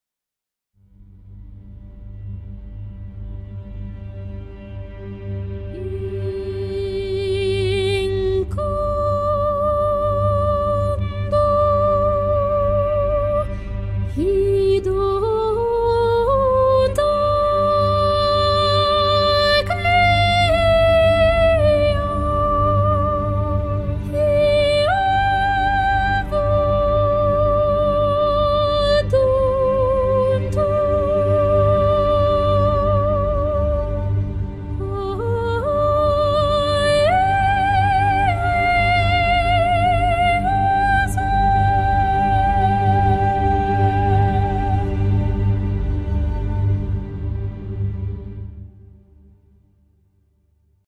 Zero‑G Ethera Gold Odyssey 是一款专注于空灵天使女声的 Kontakt 电影人声采样库，主打自适应真实连音多层纹理设计，适合影视配乐、氛围音乐与史诗创作。
• 定位：Ethera Gold 系列最新人声库，主打纯净、空灵、天使感女声。
• 音色：轻盈、空灵、带呼吸感
• 音色：更结构化、偏歌剧 / 古典质感
• 三层独立人声层叠加，搭配效果链，快速生成氛围化、史诗化人声纹理